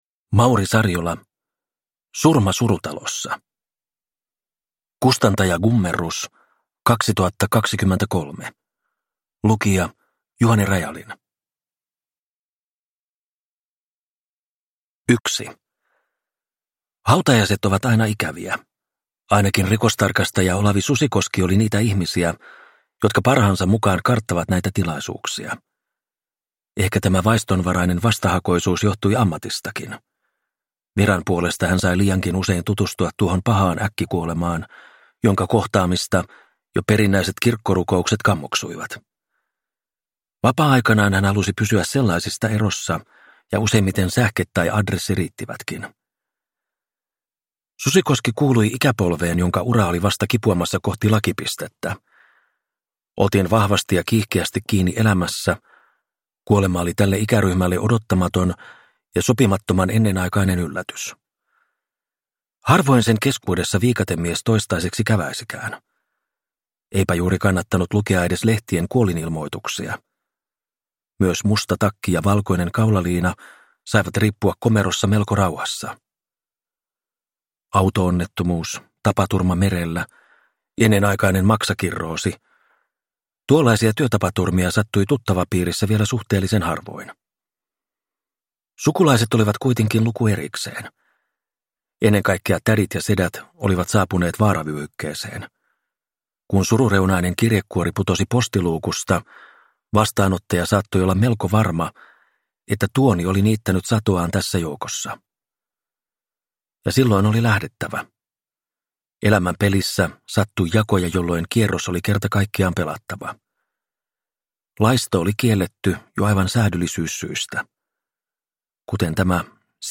Surma surutalossa – Ljudbok – Laddas ner